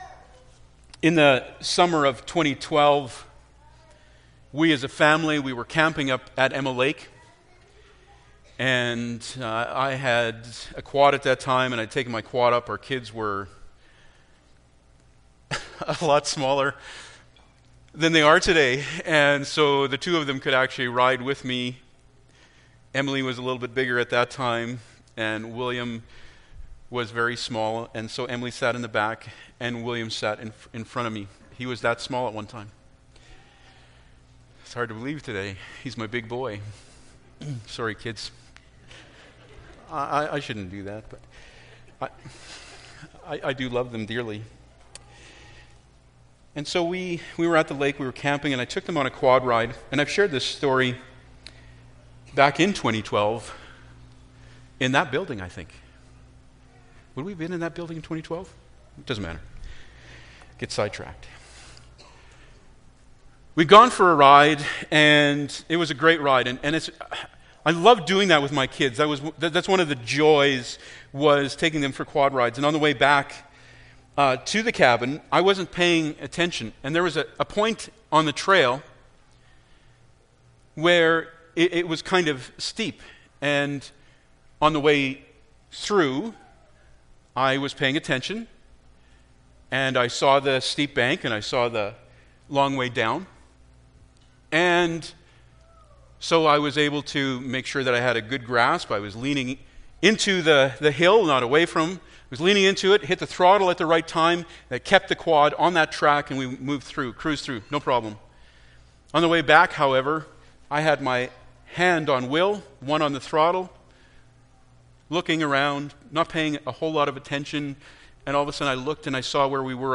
Passage: Psalm 100:1-5 Service Type: Sunday Morning Bible Text